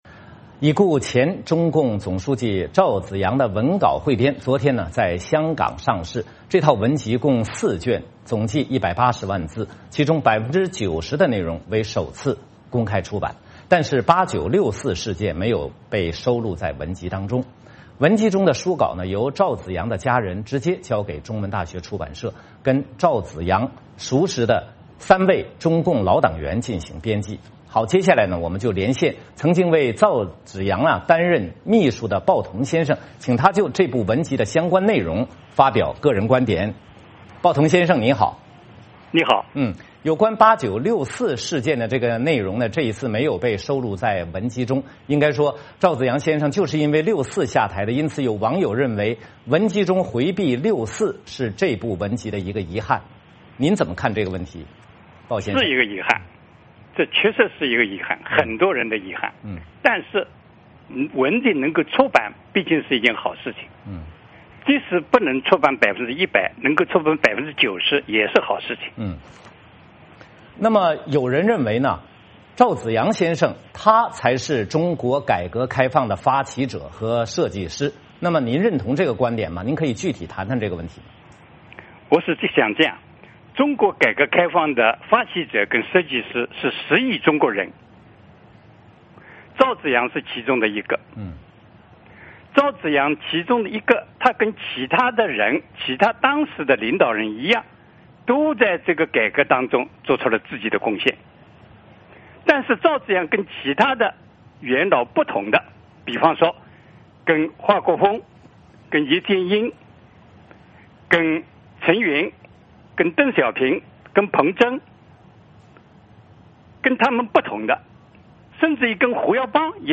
VOA连线鲍彤: 香港出版赵紫阳文集，拥赵人士倍感兴奋
接下来我们连线曾经为赵紫阳担任秘书的鲍彤先生，请他就这部《文集》的相关内容发表个人观点......